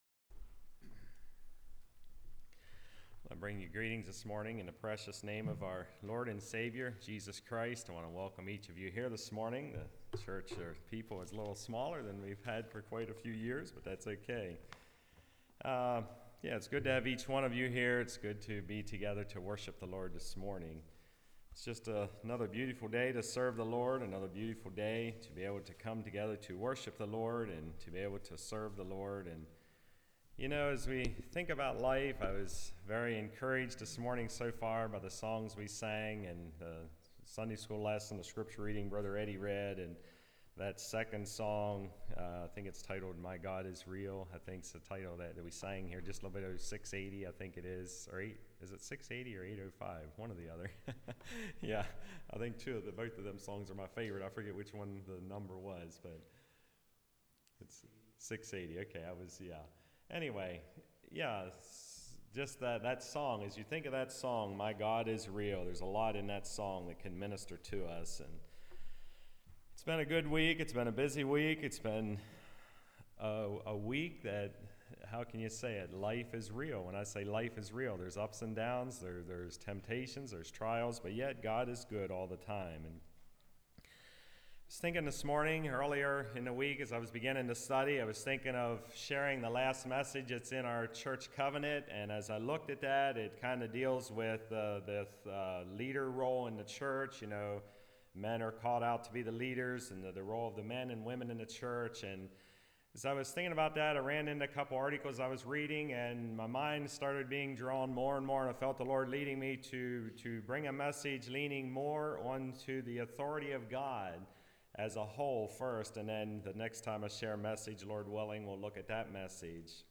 Isaiah 42:6-9 Service Type: Message Bible Text